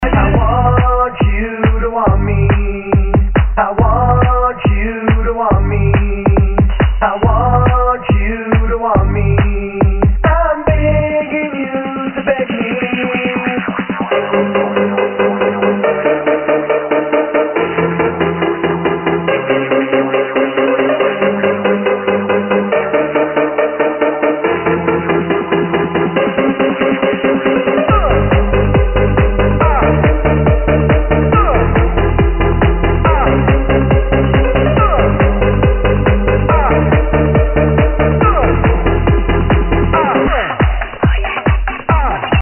~All tracks are Remixes, unless otherwise stated~